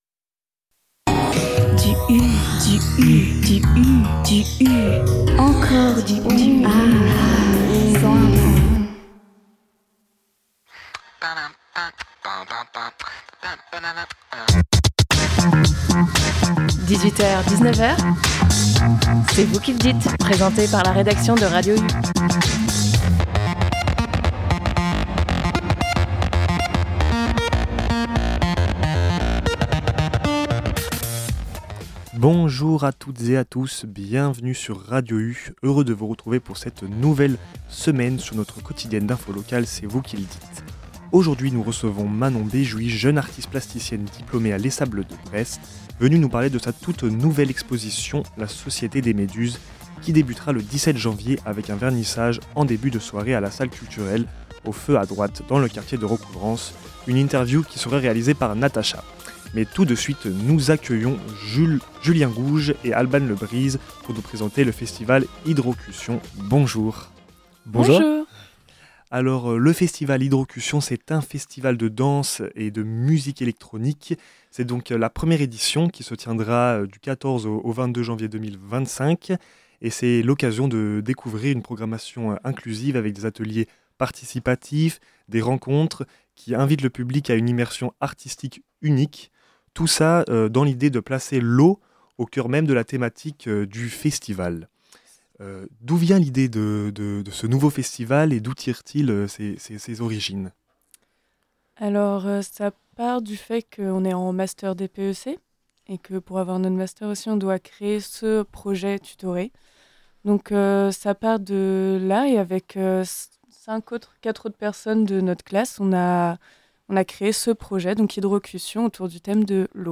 Dans cette émission du lundi, nous recevions à l’antenne deux organisateurs du festival Hydrocution.